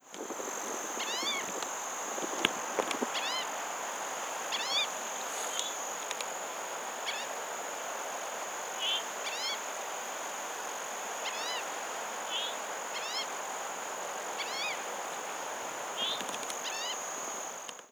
Green-tailed Towhee